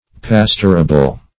Pasturable \Pas"tur*a*ble\, a. Fit for pasture.